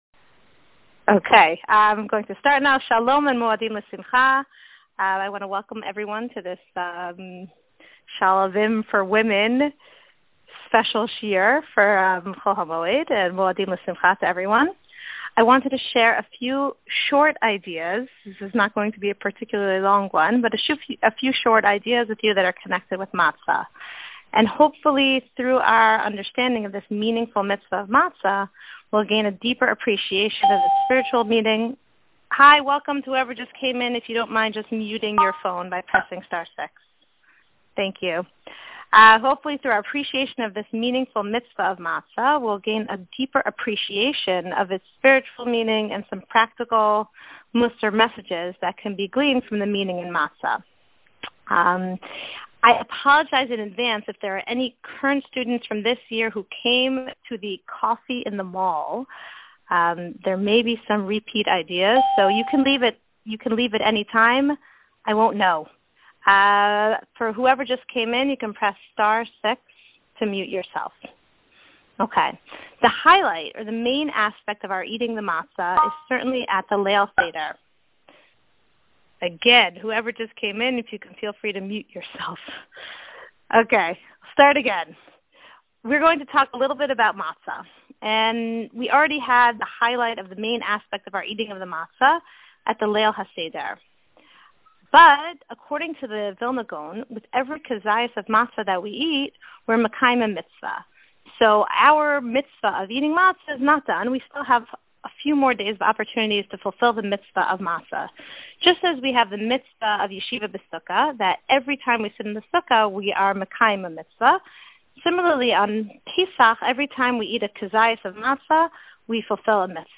Shiurim
This shiur was delivered to alumni via conference-call as part of our "Alumni Tele-Shiur" series.